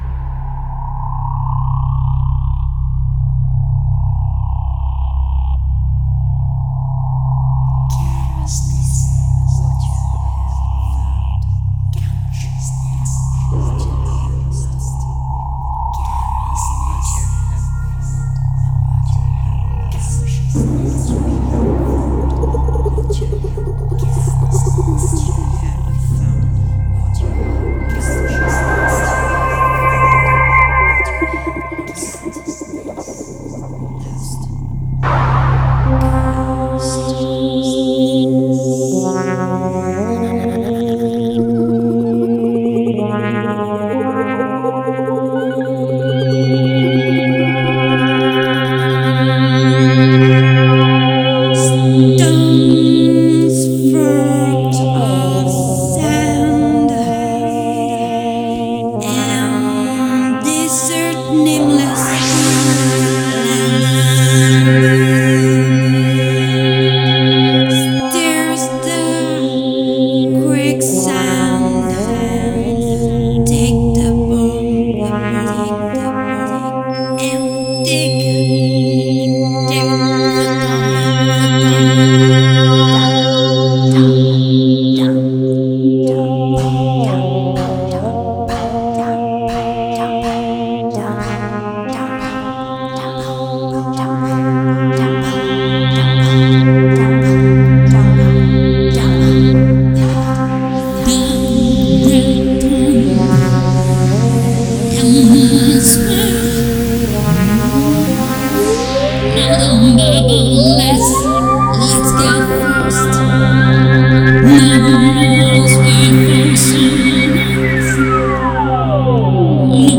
Dance & Music performance
electroacoustic music